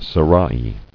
[se·ra·i]